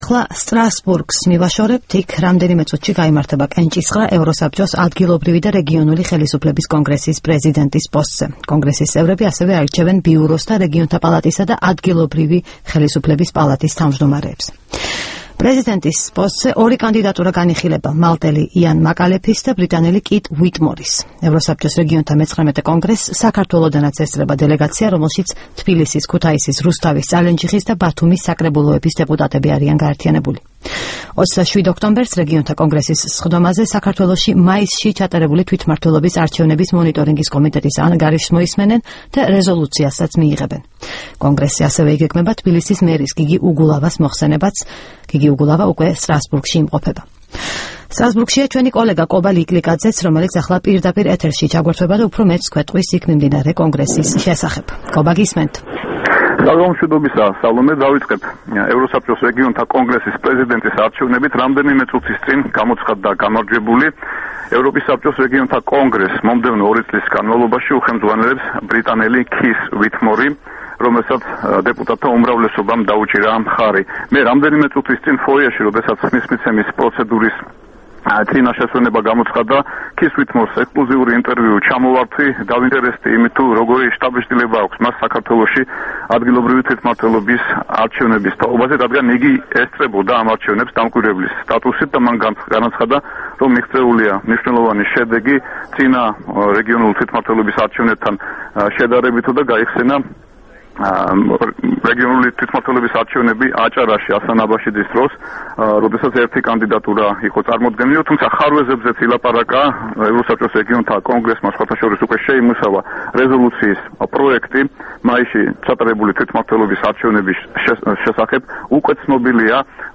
რეპორტაჟი სტრასბურგიდან